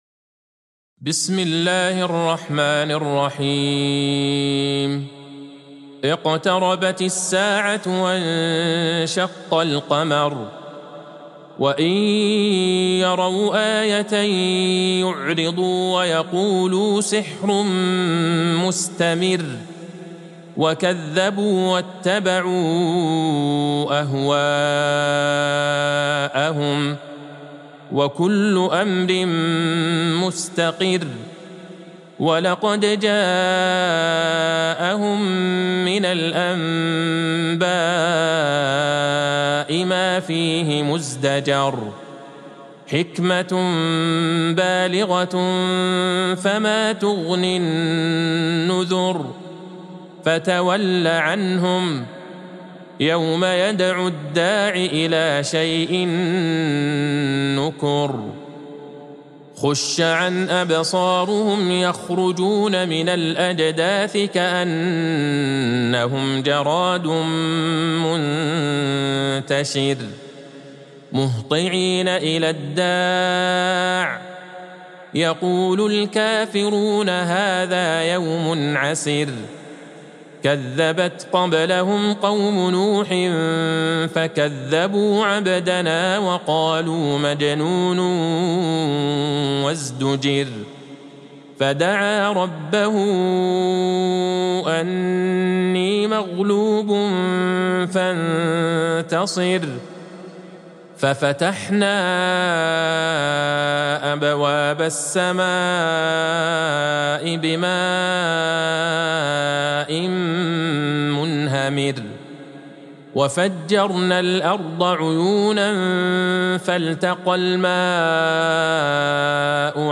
سورة القمر Surat Al-Qamar | مصحف المقارئ القرآنية > الختمة المرتلة